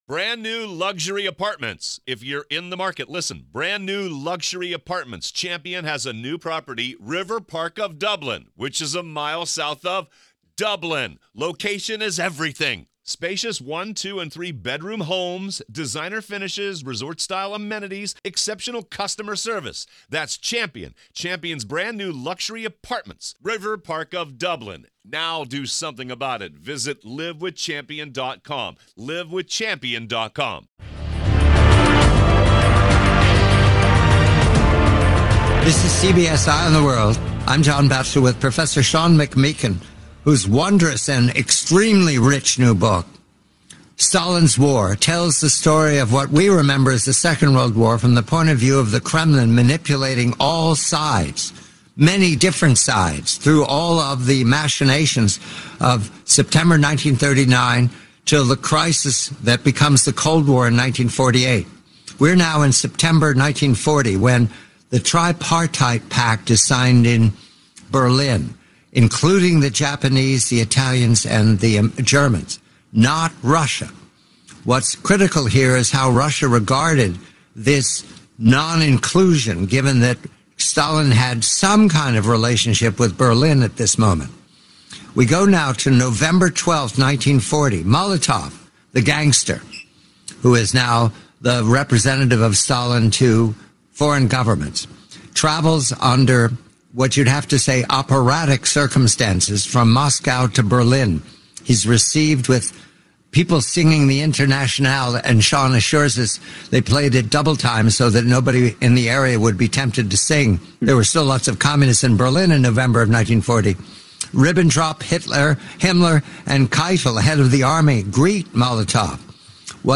Audible Audiobook – Unabridged